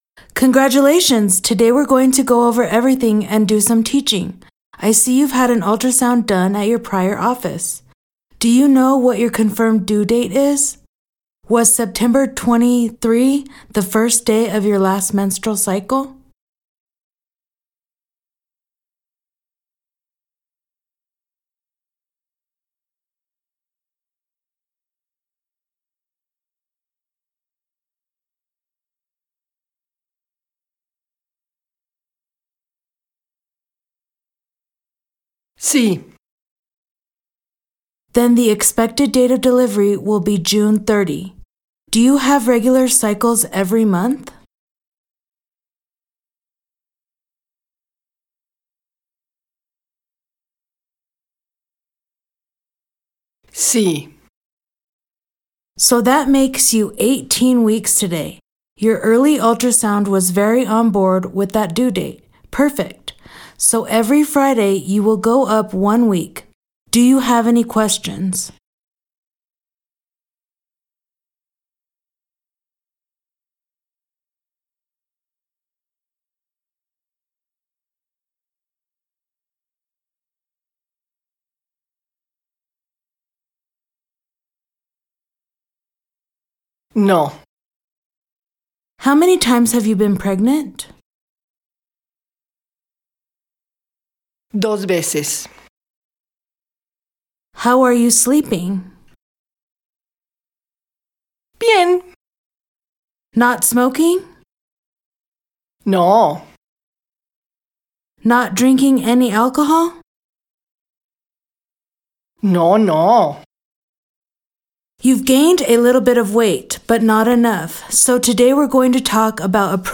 VCI-Practice-Dialogue-09-Gynecology-Prenatal-Visit-EN-SP.mp3